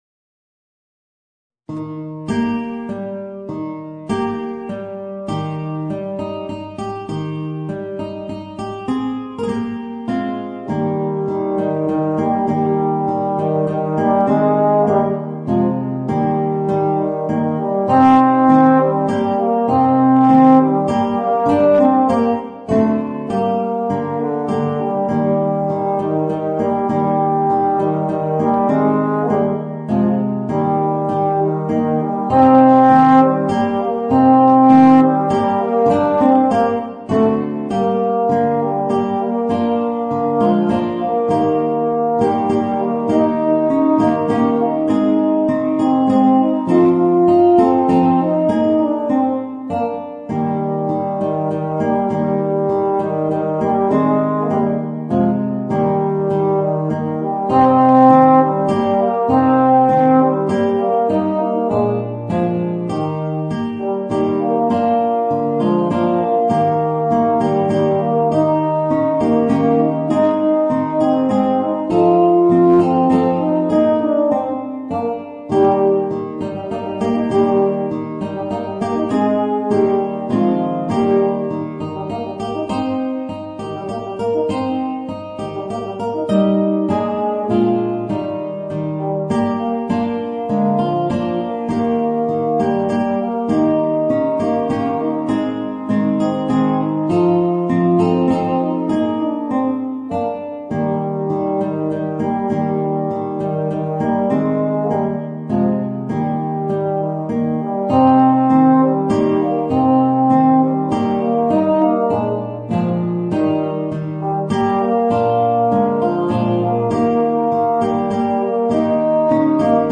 Voicing: Euphonium and Guitar